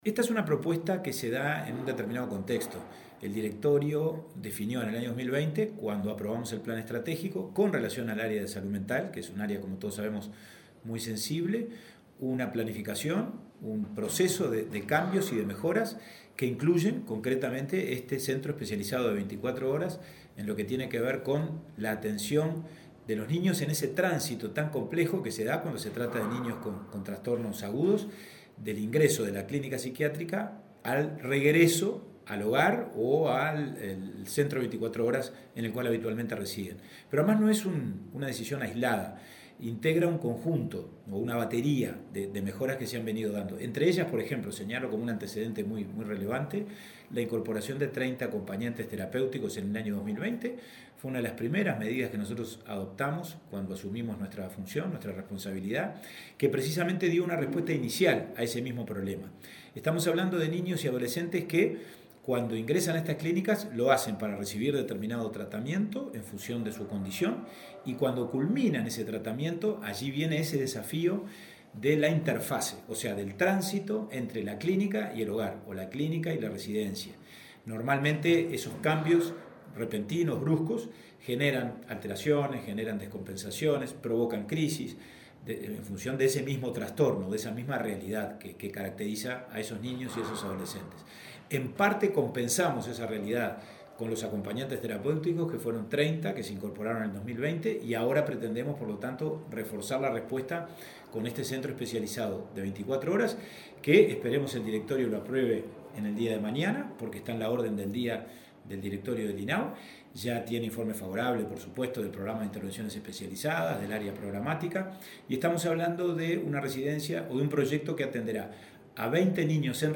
Entrevista al presidente del INAU, Pablo Abdala
Entrevista al presidente del INAU, Pablo Abdala 13/07/2022 Compartir Facebook X Copiar enlace WhatsApp LinkedIn El presidente del Instituto del Niño y el Adolescente de Uruguay (INAU), Pablo Abdala, dialogó con Comunicación Presidencial acerca de la apertura de un centro de breve estadía para internos con trastornos psiquiátricos.